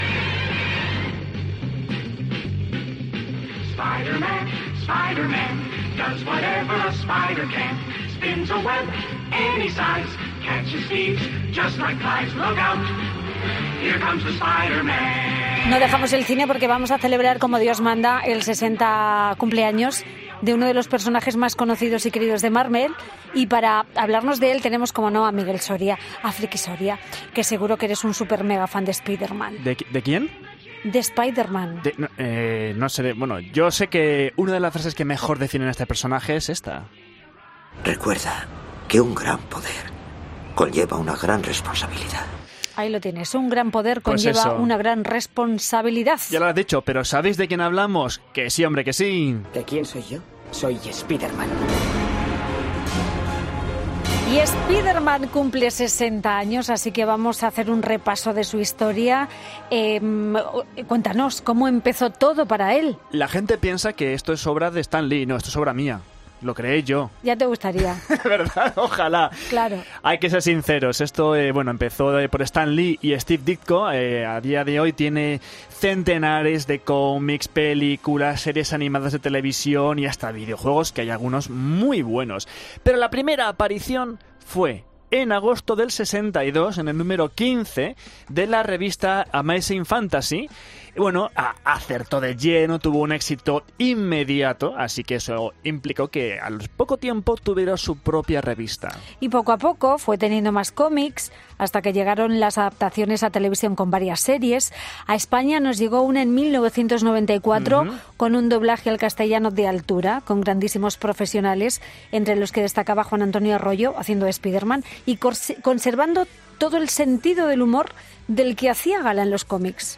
Sesenta años de Spider-Man: repasamos sus orígenes y hablamos con sus voces en español